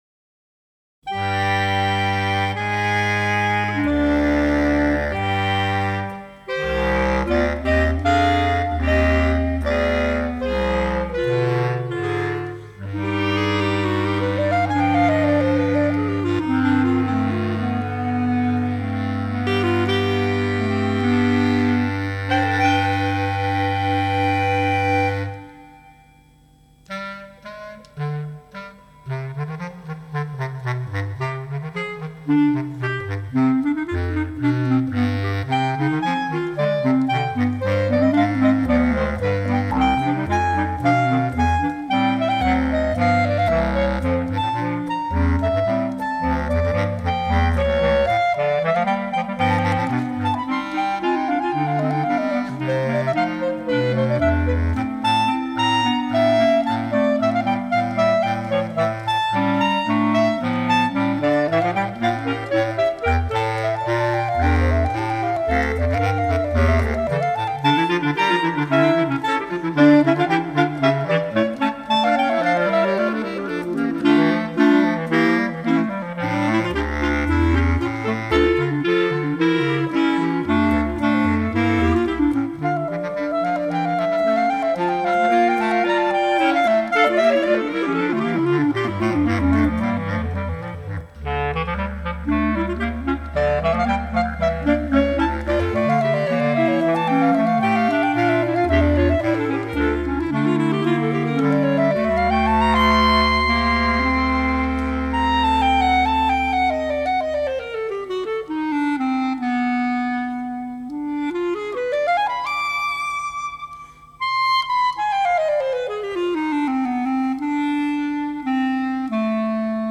Bb Clarinet Range: E1 to E4. Bass Clarinet Lowest Note: D1